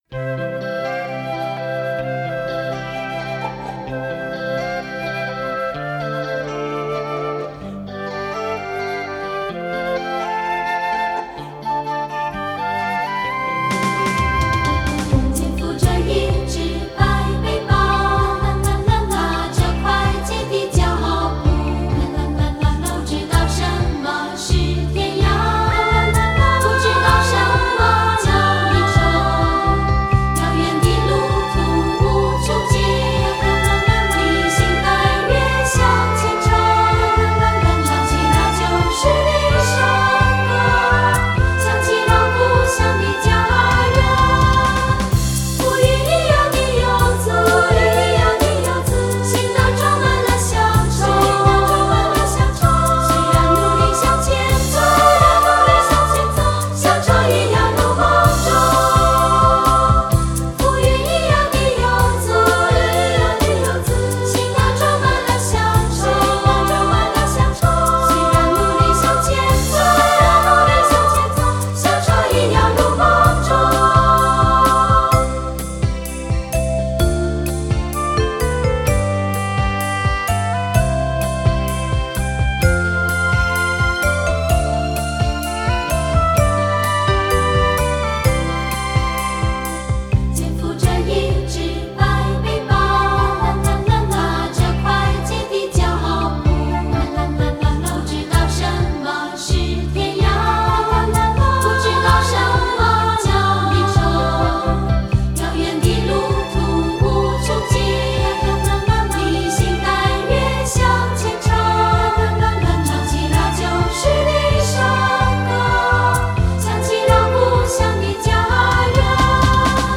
合唱
東方天使的歌聲
優美的和聲